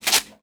R - Foley 82.wav